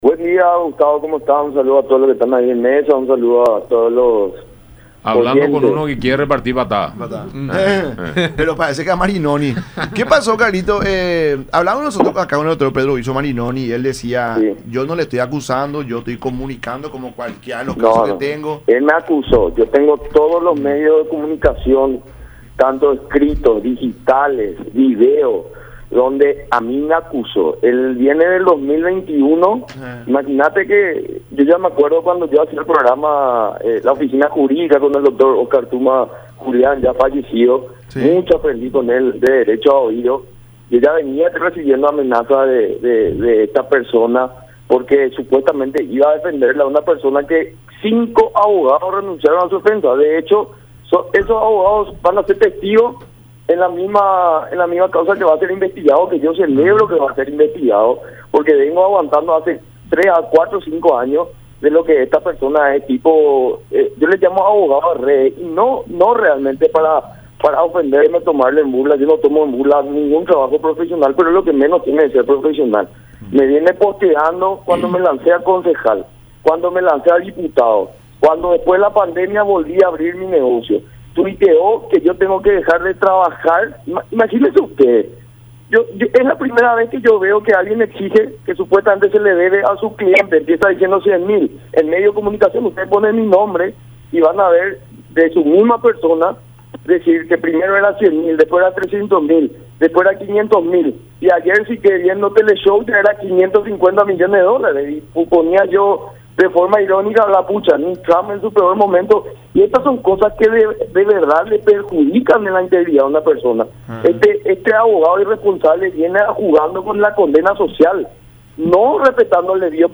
en charla con La Mañana De Unión a través de Unión TV y radio La Unión